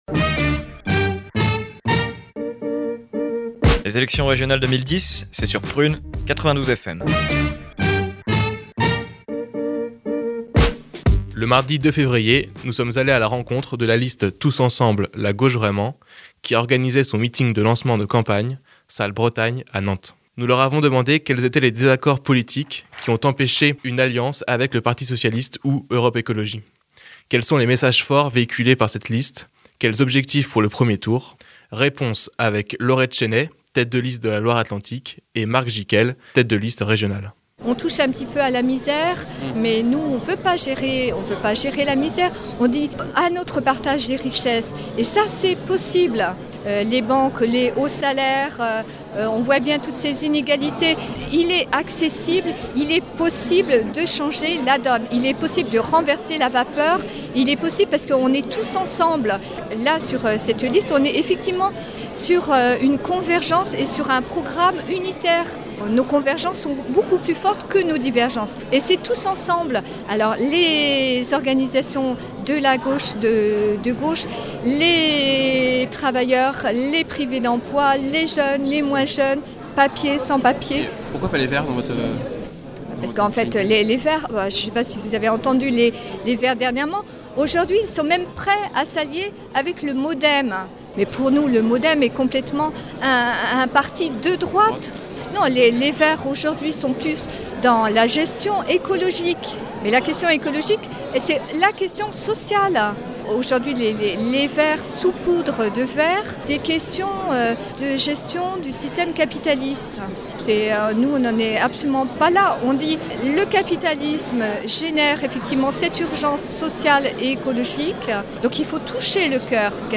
La liste Tous Ensemble, la Gauche Vraiment organisait son meeting de lancement de campagne, le mardi 2 février à la Salle Bretagne à Nantes, nous sommes allés à leur rencontre pour en connaître plus. Nous leur avons demandé quels étaient les désaccords politiques qui avaient empêché une alliance avec le Parti Socialiste ou Europe Écologie.